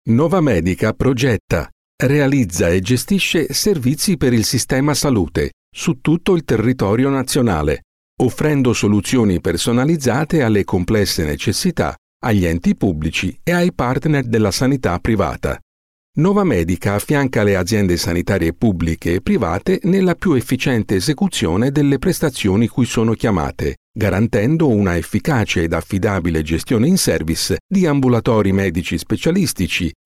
Profonde, Chaude, Corporative, Commerciale, Senior, Mature
Corporate